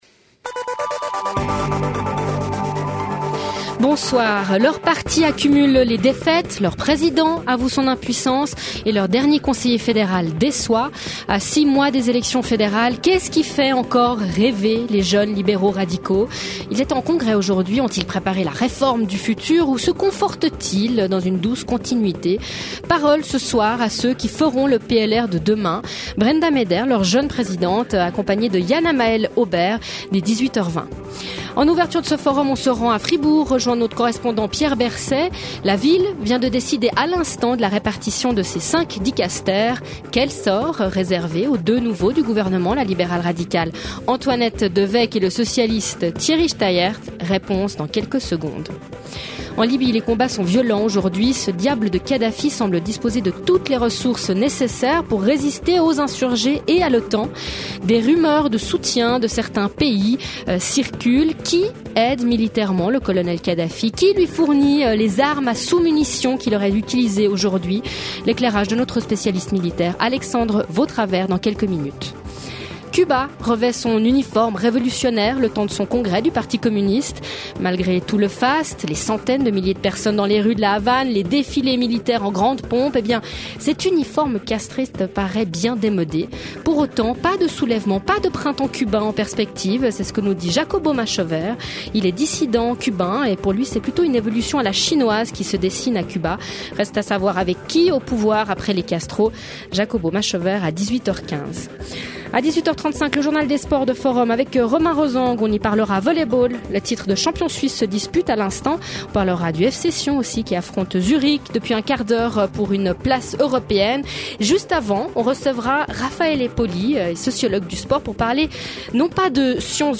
7 jours sur 7, Forum questionne en direct les acteurs de l’actualité, ouvre le débat sur les controverses qui animent la vie politique, culturelle et économique.